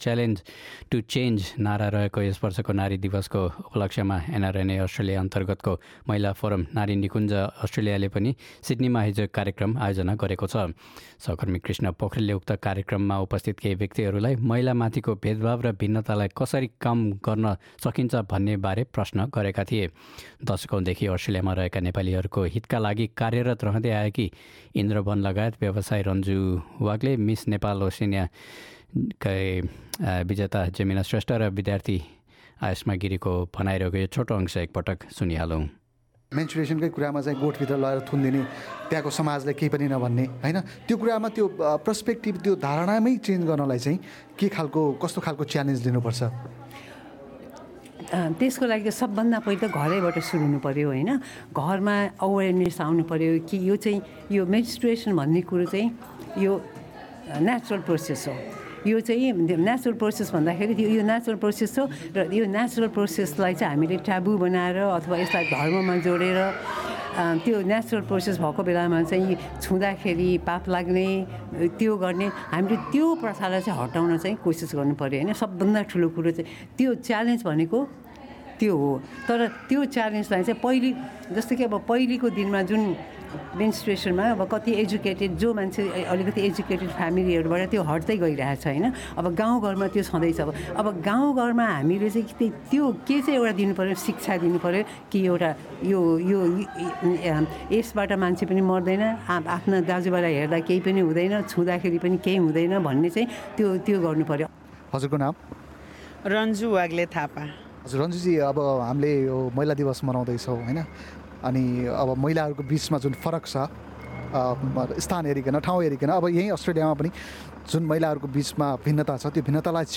मार्च ८ को दिन विश्वभर अन्तर्राष्ट्रिय महिला दिवस मनाइँदै छ। "च्यालेन्ज टु चेन्ज" नाराका साथ मनाइने यस वर्षको अन्तर्राष्ट्रिय महिला दिवसको उपलक्ष्यमा गैर आवासीय नेपाली सङ्घ अस्ट्रेलिया अन्तर्गत रहेको नारी निकुञ्ज अस्ट्रेलियाद्वारा सिड्नीमा गत शनिवार एक विशेष कार्यक्रम आयोजना गरिएको छ।